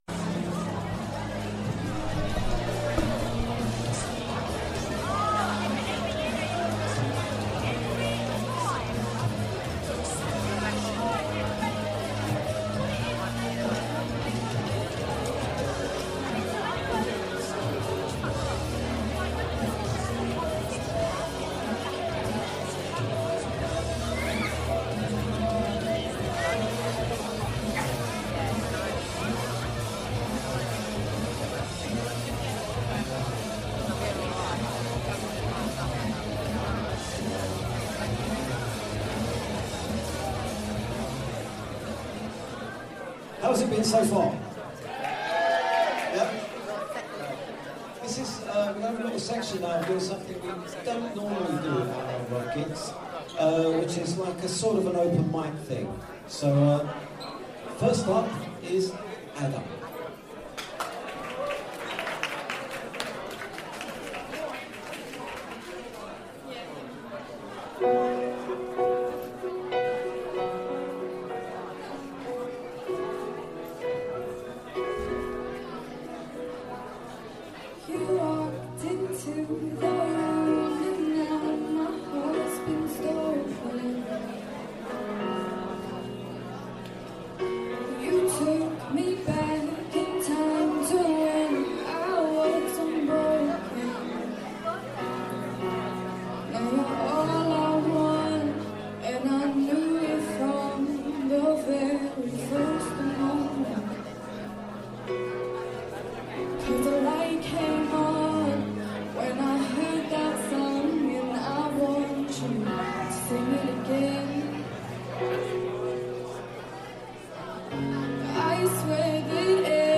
Rock Gig Nov 2017